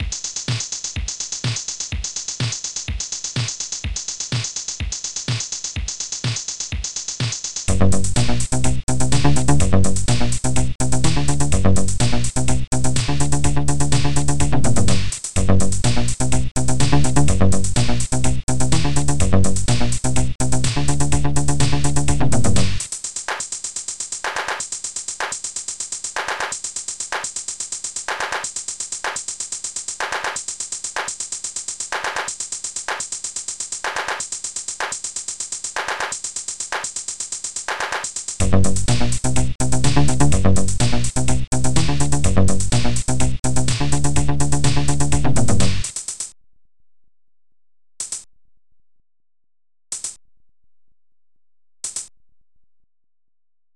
bassdrum2
popsnare2
claps1
hihat2
shaker
digiharp